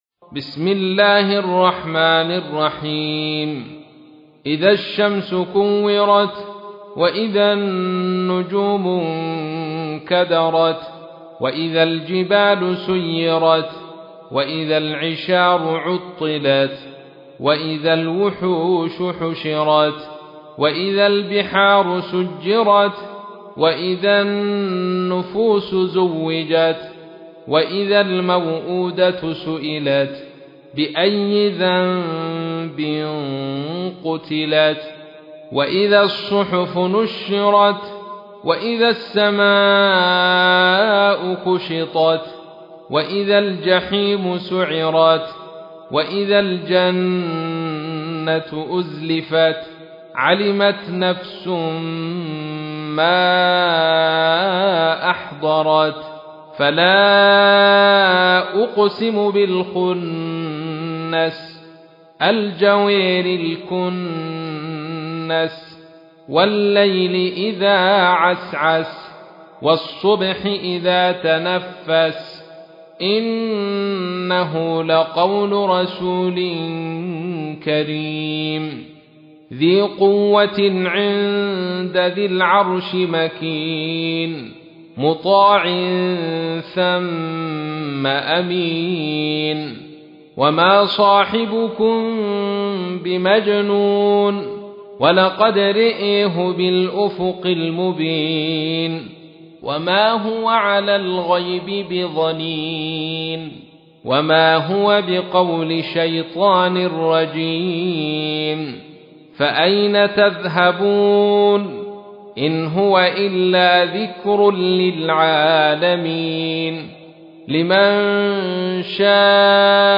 تحميل : 81. سورة التكوير / القارئ عبد الرشيد صوفي / القرآن الكريم / موقع يا حسين